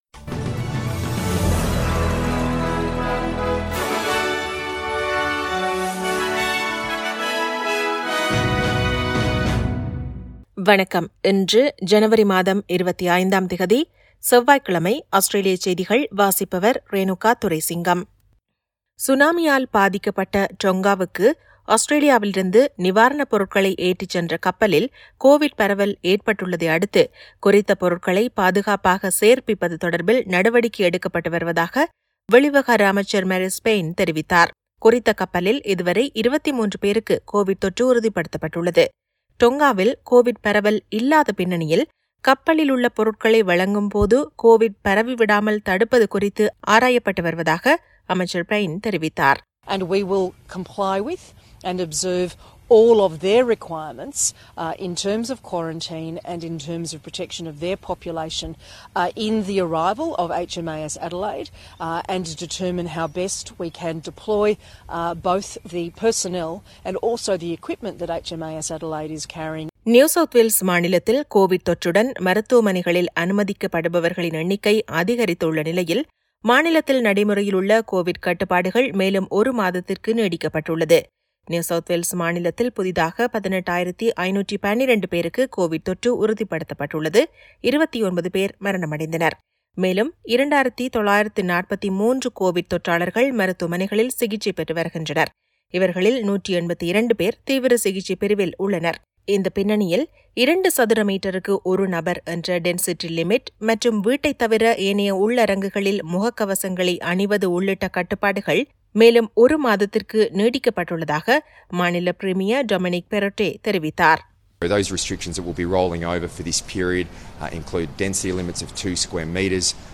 Australian news bulletin for Tuesday 25 Jan 2022.